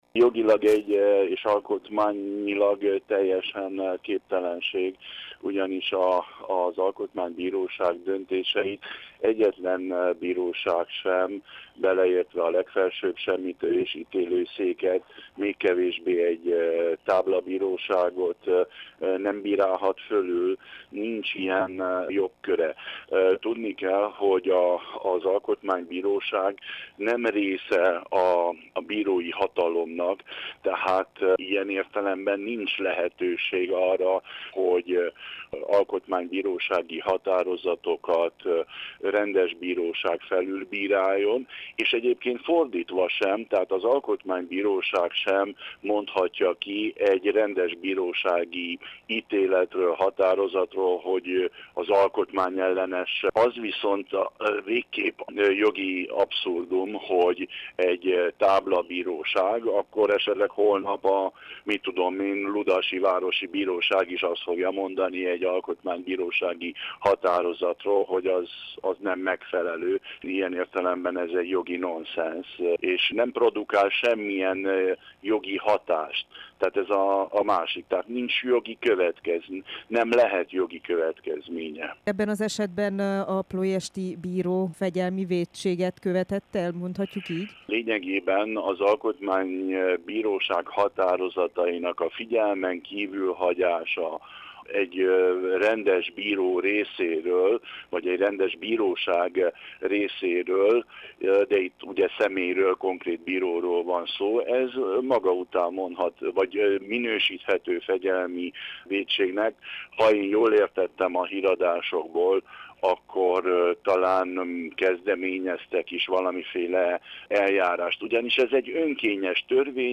Varga Attila alkotmánybírót kérdezte